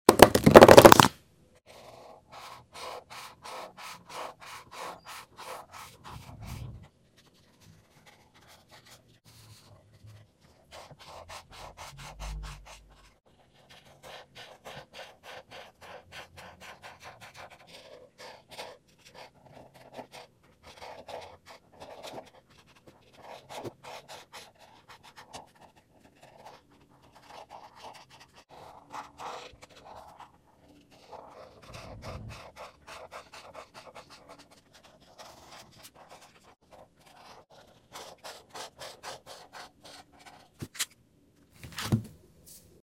😊🎨 ASMR coloring with markers from my bold and easy coloring books